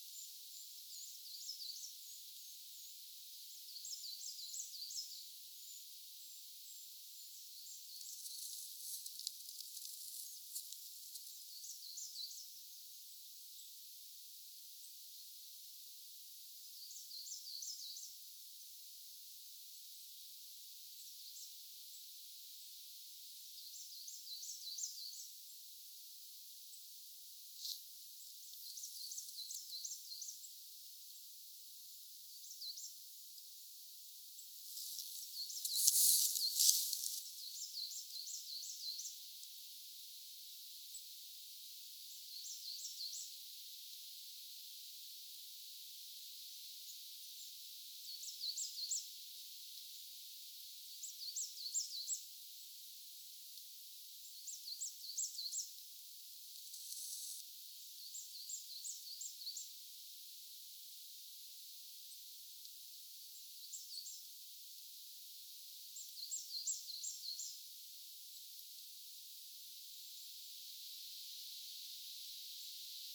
kuusitiainen laulaa
kuusitiainen_laulaa.mp3